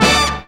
JAZZ STAB 11.wav